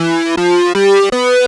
Track 16 - Synth 07.wav